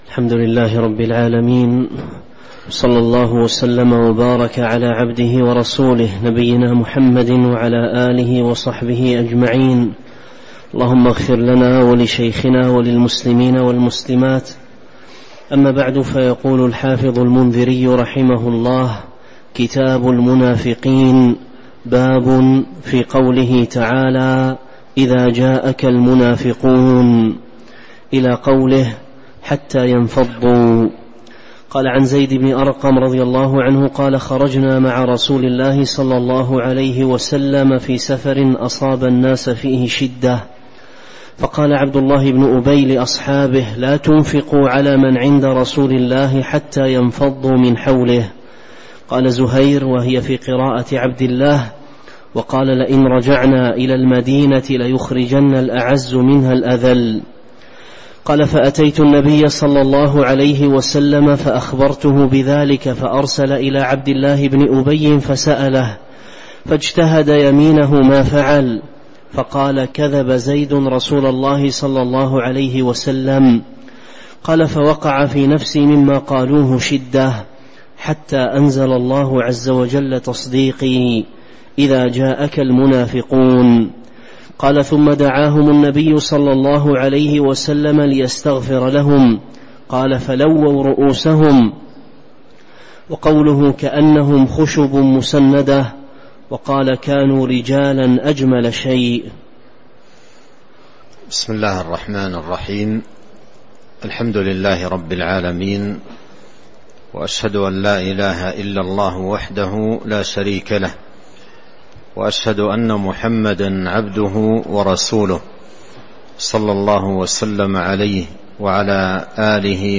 تاريخ النشر ٢١ ذو الحجة ١٤٤٣ هـ المكان: المسجد النبوي الشيخ